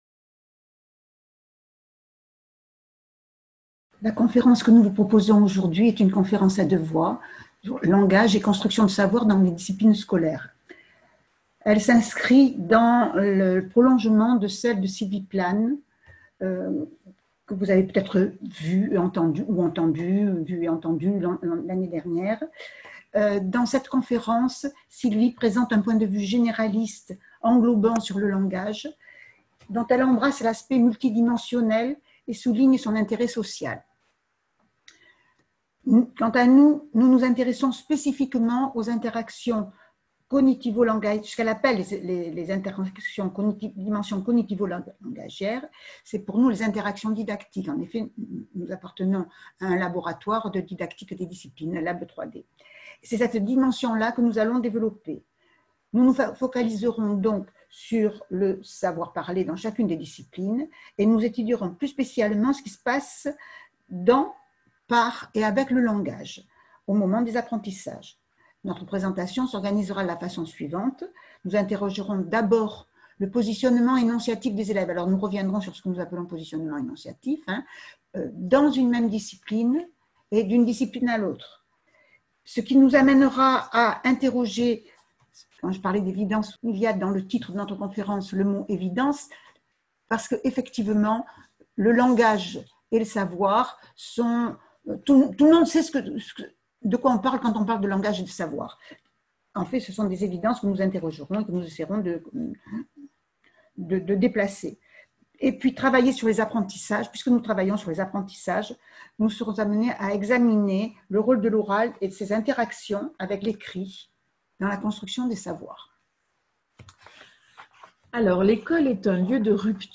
Une conférence